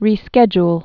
(rē-skĕjl)